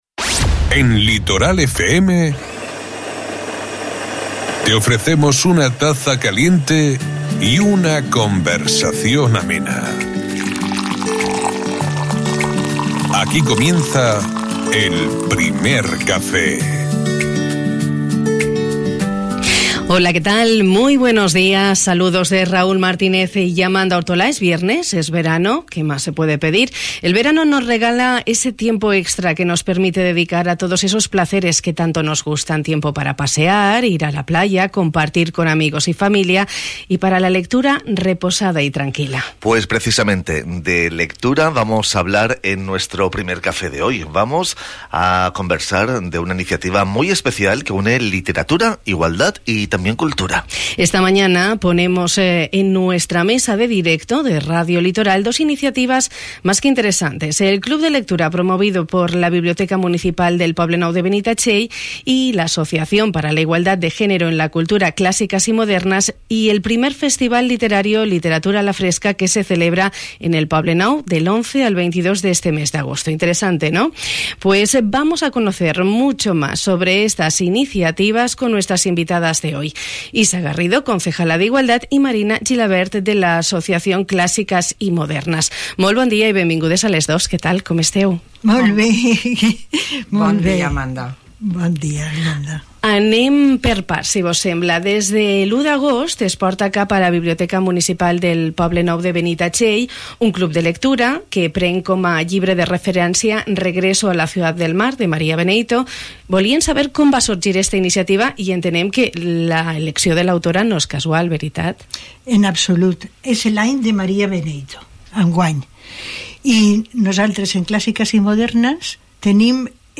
En el Primer Café de hoy hemos conversado sobre una iniciativa muy especial que une literatura, igualdad y cultura.